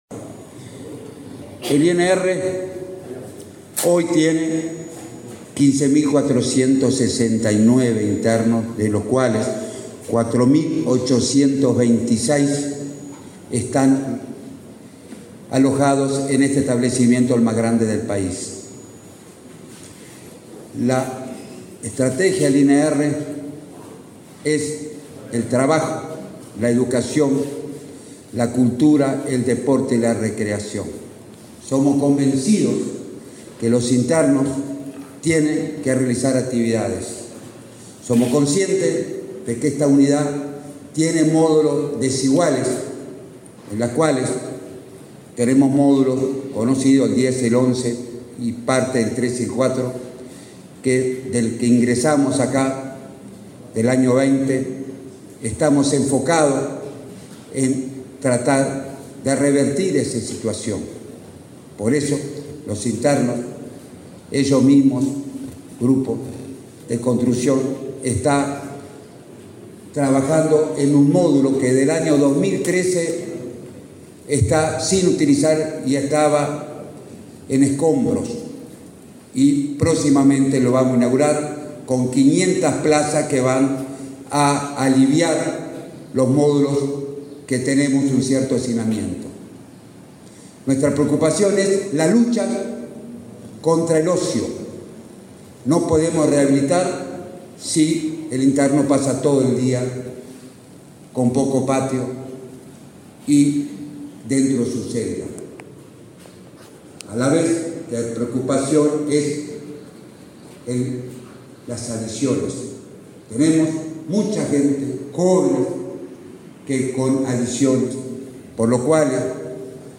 Palabras de autoridades en acto de firma de convenio entre el INR y UTU